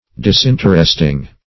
Search Result for " disinteresting" : The Collaborative International Dictionary of English v.0.48: Disinteresting \Dis*in"ter*est*ing\, a. Uninteresting.
disinteresting.mp3